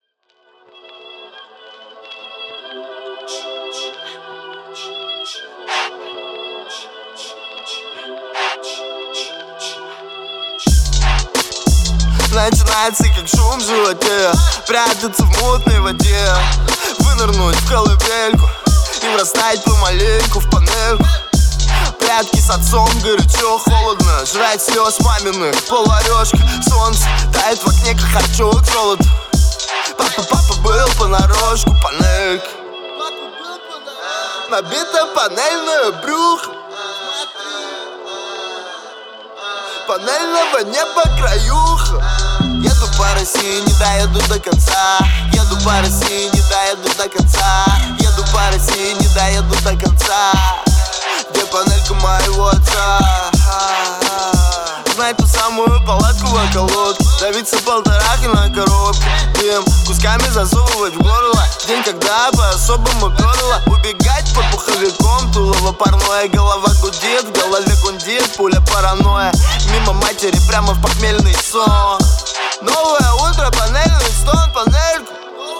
• Качество: 320, Stereo
громкие
русский рэп
качающие
Bass